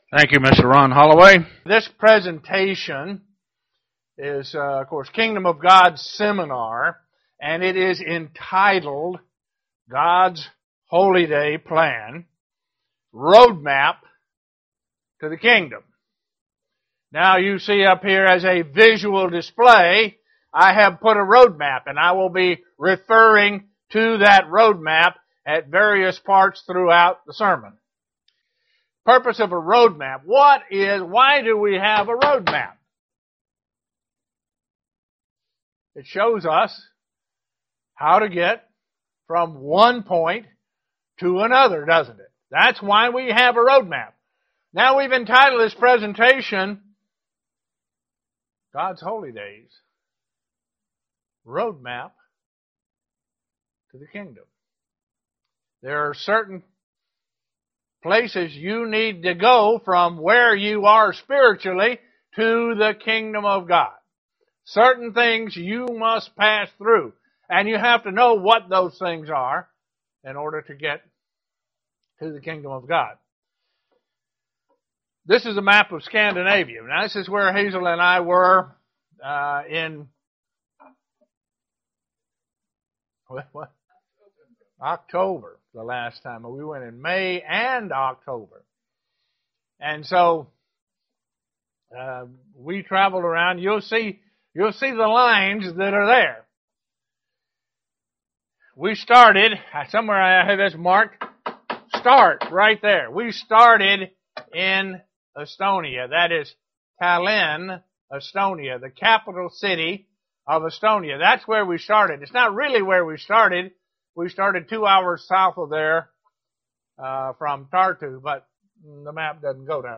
Kingdom of God Seminar.
UCG Sermon Studying the bible?